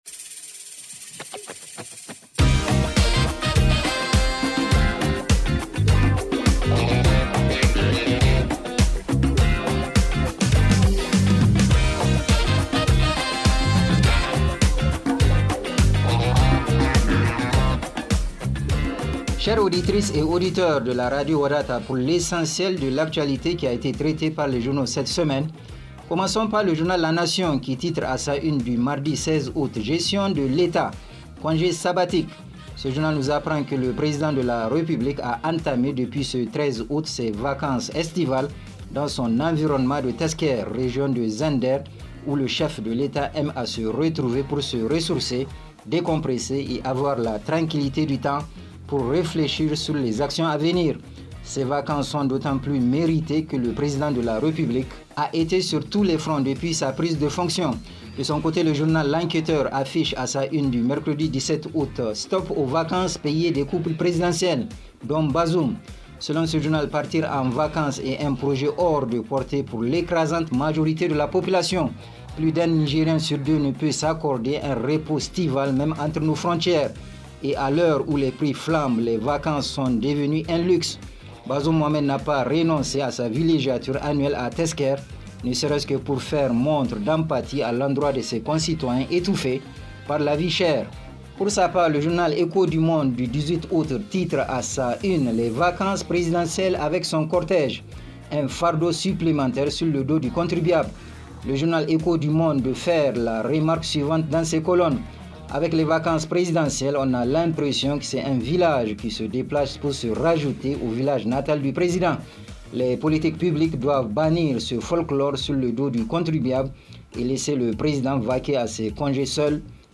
Revue de presse en français